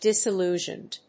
音節dìs・il・lú・sioned 発音記号・読み方
/ˌdɪsɪˈluʒʌnd(米国英語), ˌdɪsɪˈlu:ʒʌnd(英国英語)/